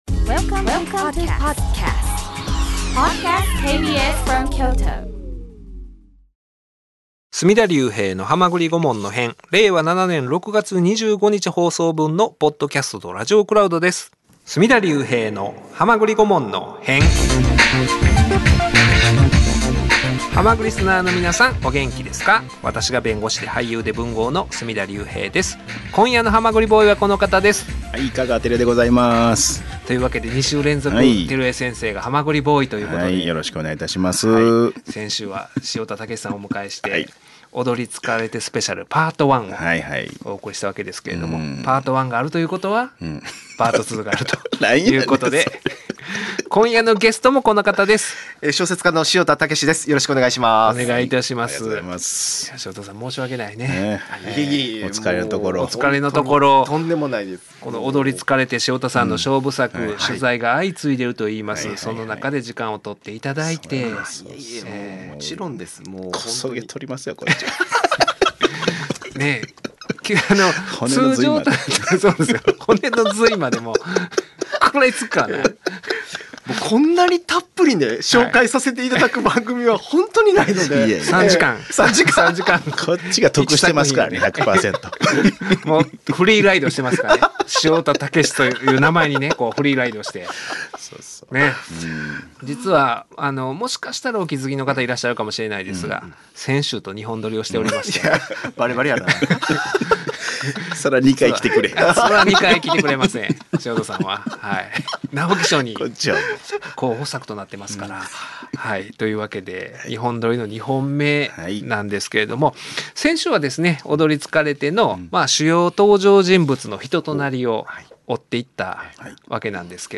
【KBS京都ラジオ 水曜日 19:30～21:00 オンエア】お騒がせのニュースやスキャンダル、日常のささいな出来事も法律目線でとらえることで、より深くより興味深い案件に大変身！